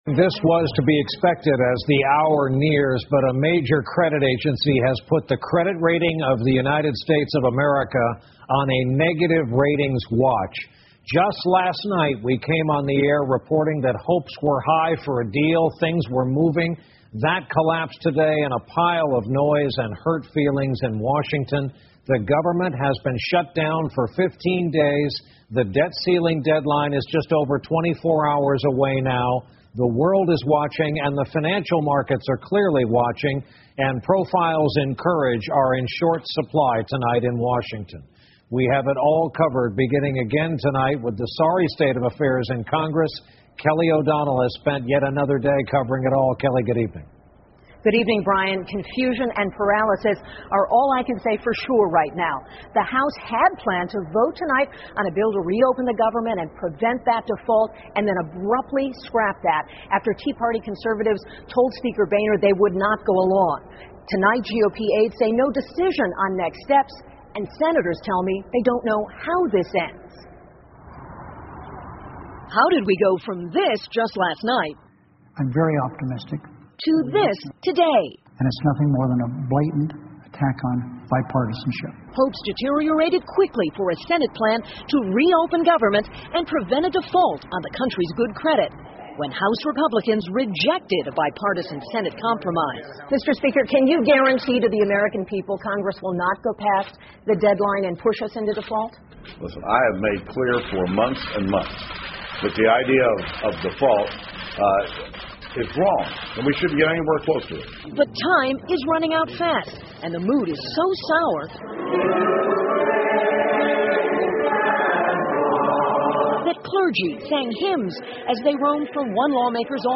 NBC晚间新闻 两党妥协的希望变得渺茫 听力文件下载—在线英语听力室